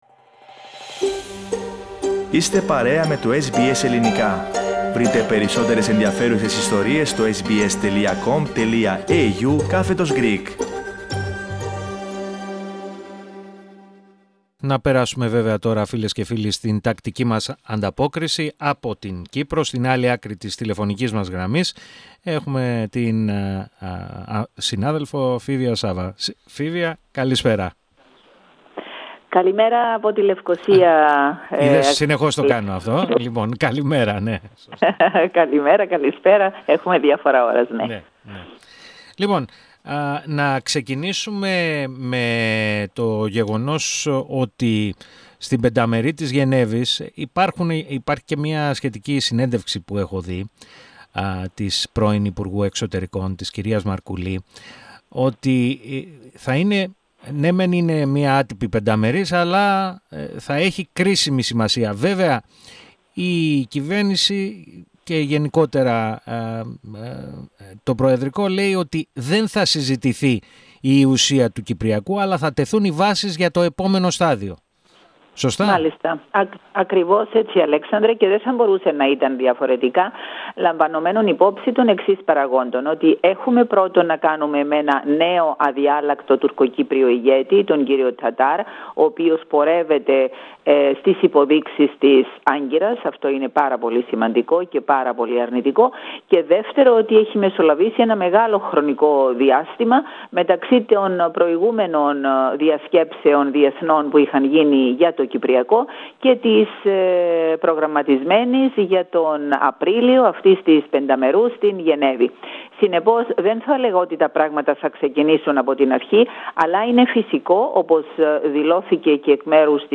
Ακούστε ολόκληρη την ανταπόκριση από την Κύπρο.